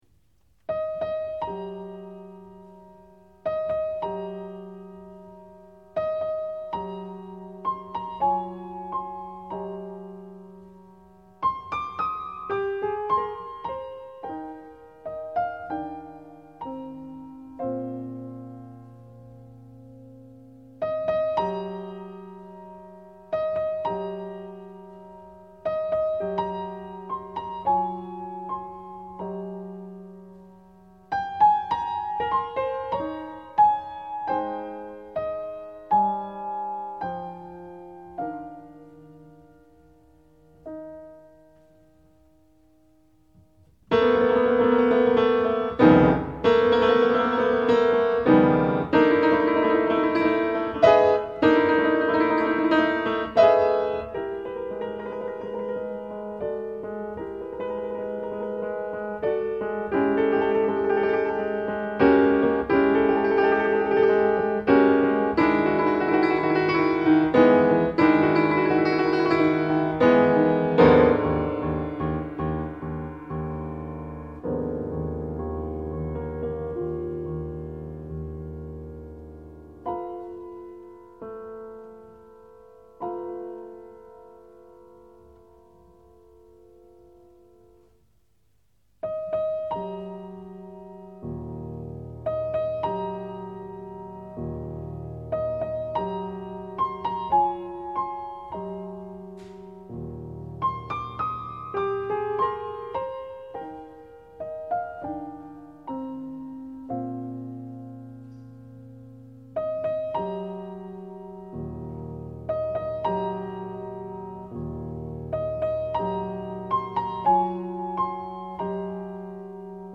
Playlist douce et intime